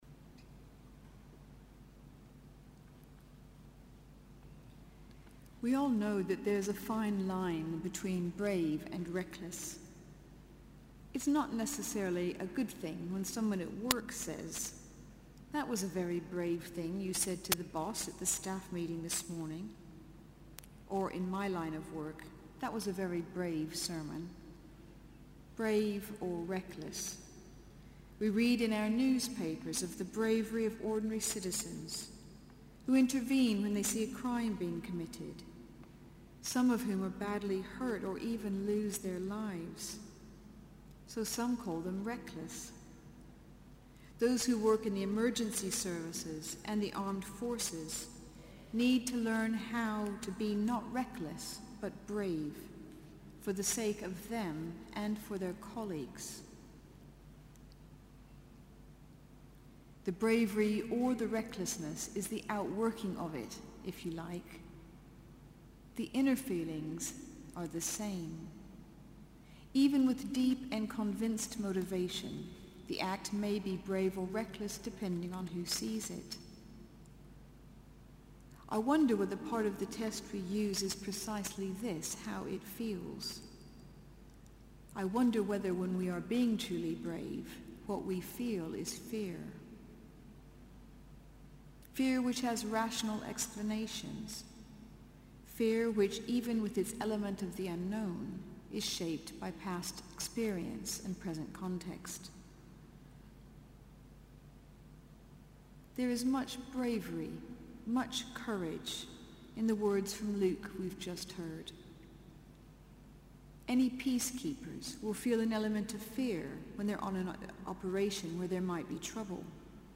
Sermon: Good Friday 2014 - Part 2